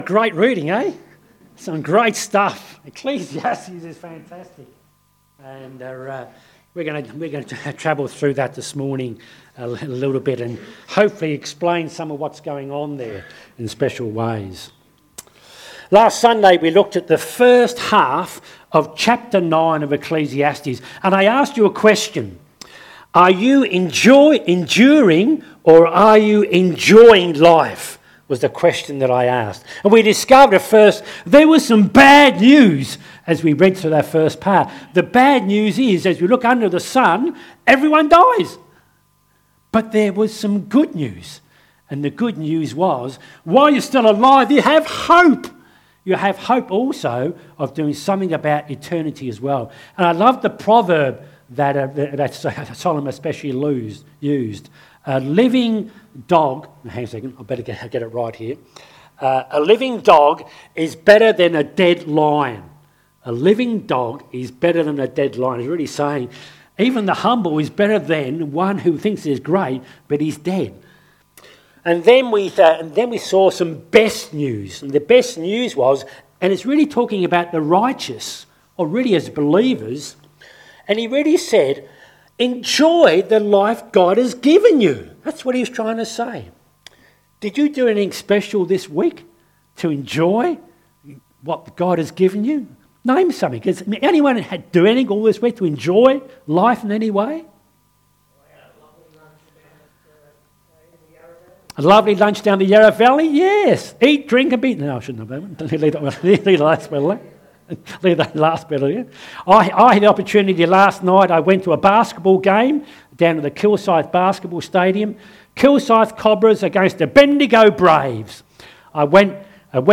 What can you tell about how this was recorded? Lilydale Baptist Church 14th July, 2024 10am Service Sermon Ecclesiastes Study 10 “Wisdom & Folly”